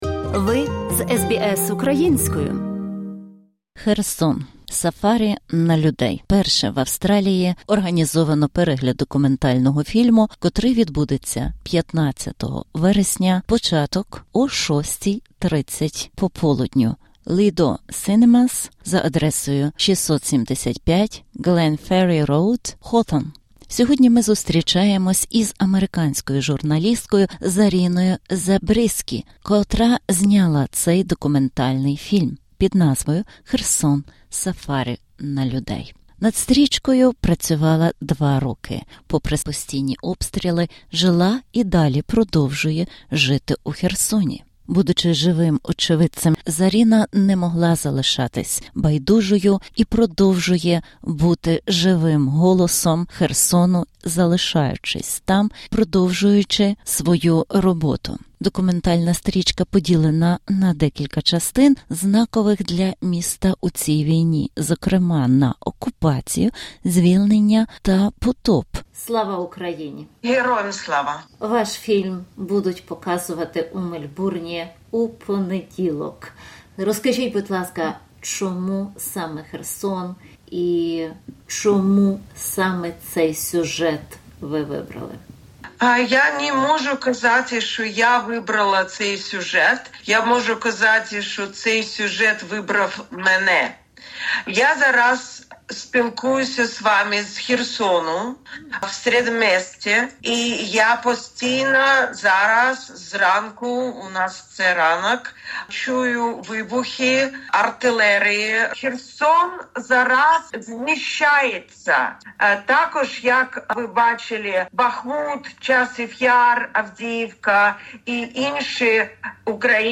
В цьому інтерв'ю ми розмовляємо з американською журналісткою та письменницею